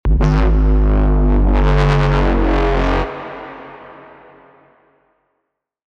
drum & bass samples
Foghorn Bass 16 G# (FX)
Foghorn-Bass-16-G-Fx.mp3